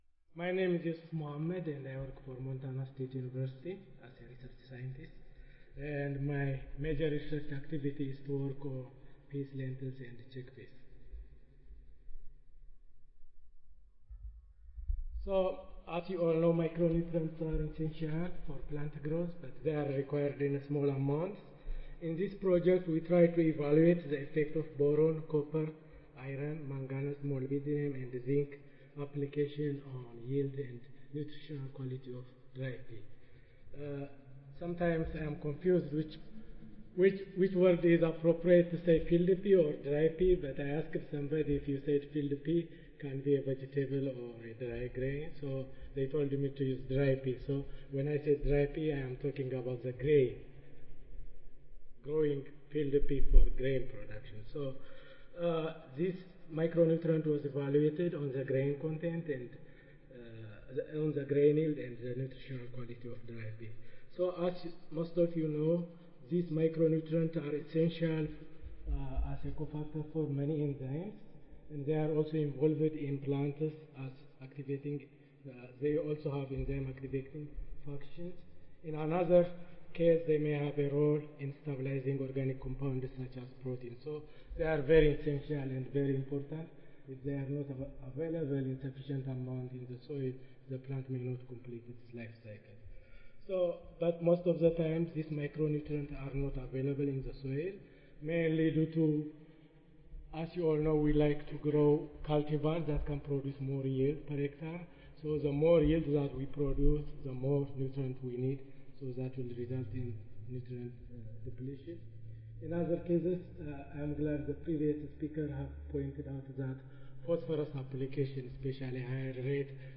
Montana State University Audio File Recorded Presentation